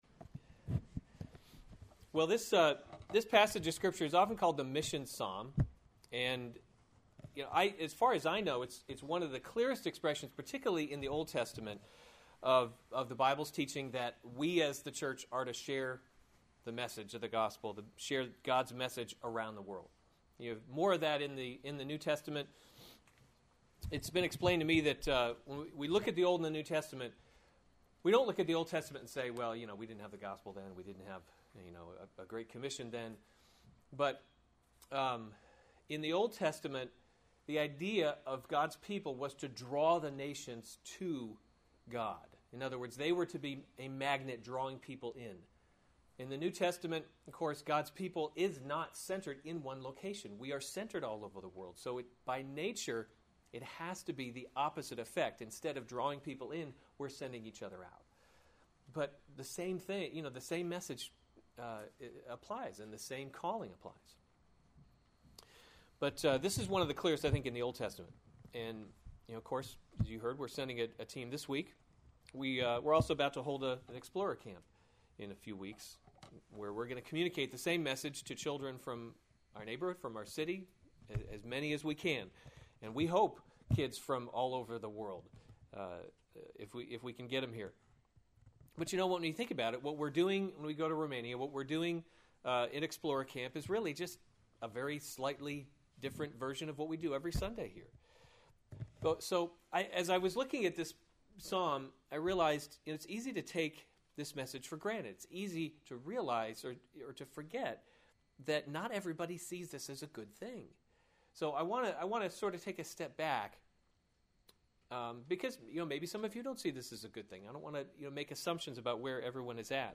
June 27, 2015 Psalms – Summer Series series Weekly Sunday Service Save/Download this sermon Psalm 67 Other sermons from Psalm Make Your Face Shine upon Us To the choirmaster: with […]